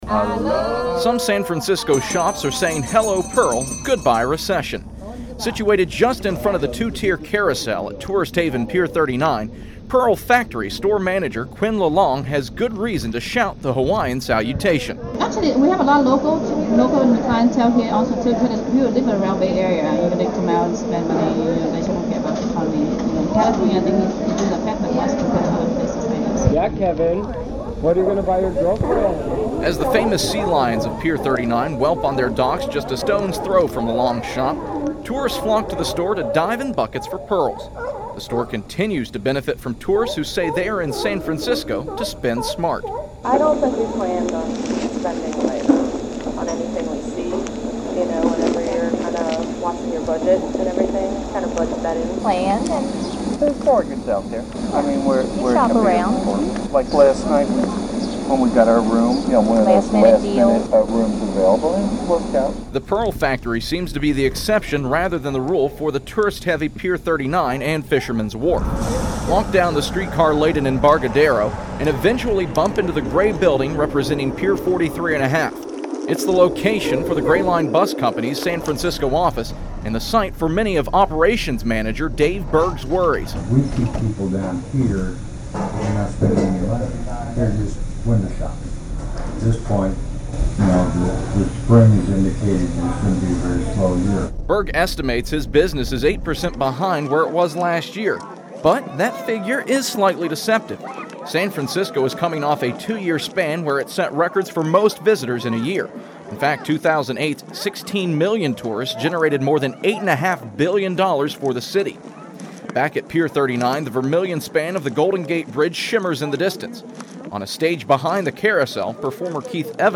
2009 Second Place Radio Broadcast News Winner